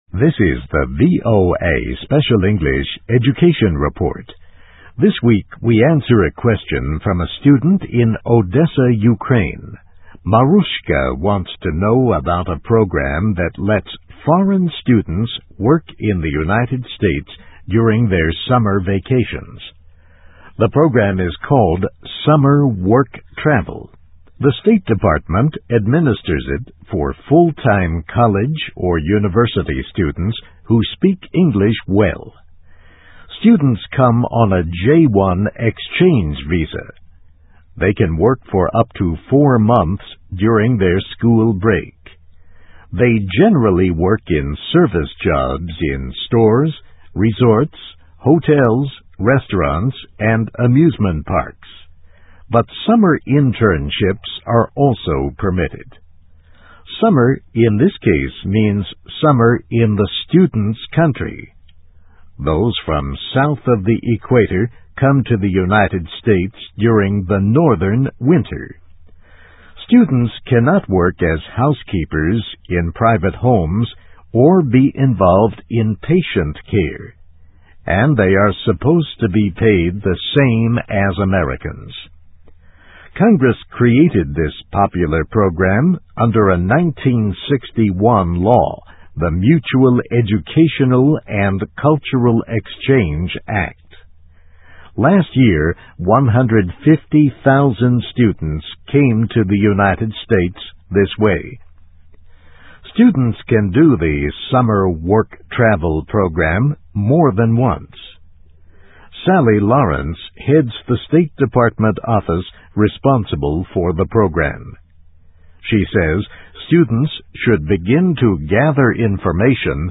Topic: Last year, 150,000 foreign college students took part in this J-1 visa exchange program during their summer vacations. Transcript of radio broadcast.